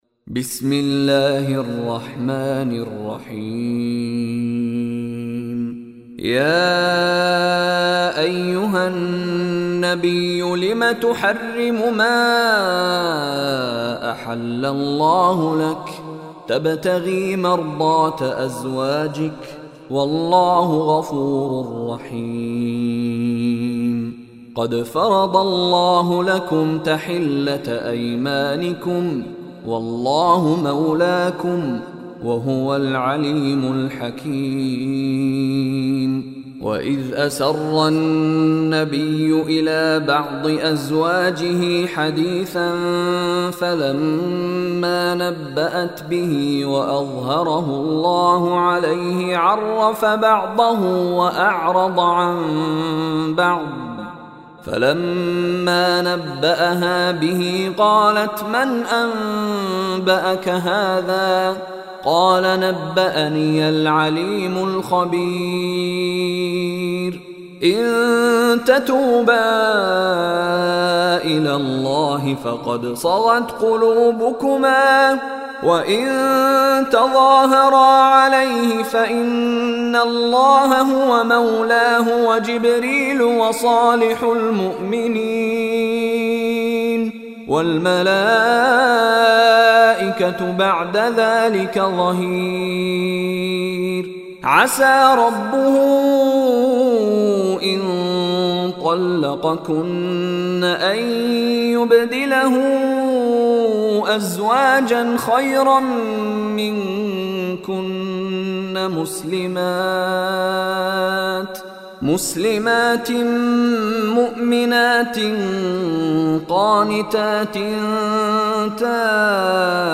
Surah Tahrim Recitation by Mishary Rashid Alafasy
Surah Tahrim is 66th chapter of Holy Quran. Listen online mp3 tilawat / recitation of Surah Tahrim in the beautiful voice of Sheikh Mishary Rashid Alafasy.